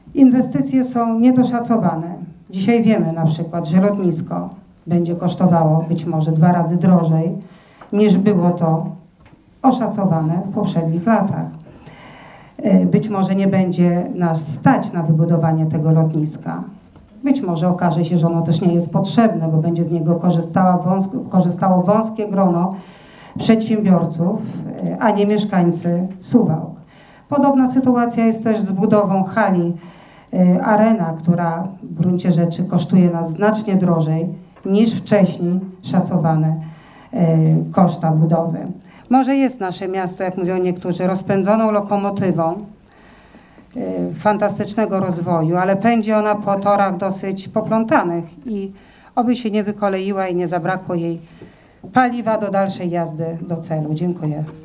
Głos w tej sprawie zabrała Irena Schabieńska z klubu PiS.